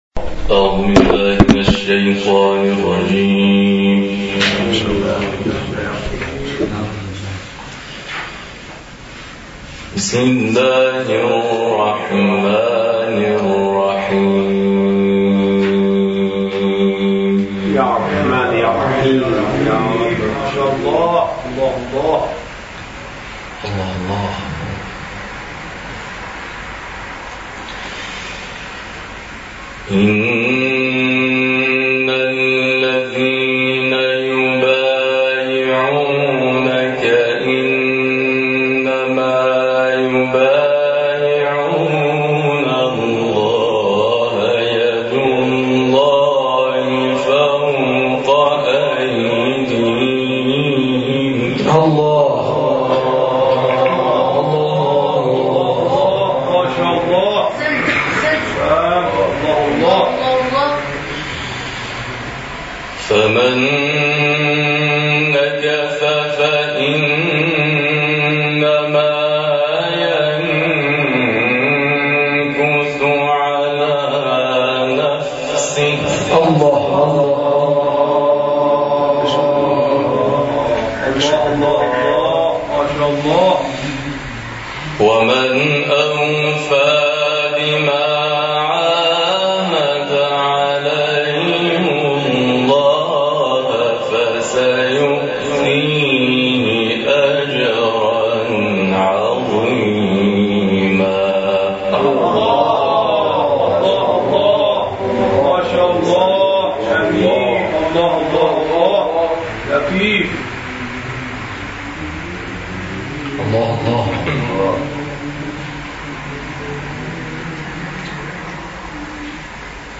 تلاوت آیاتی از کلام‌الله مجید
این تلاوت 20 دقیقه‌ای هفته گذشته در جلسه آموزش قرآن